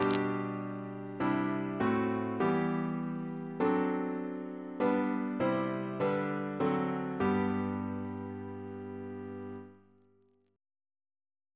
Single chant in G Composer: R. H. Stanley Reference psalters: ACP: 285; H1940: 624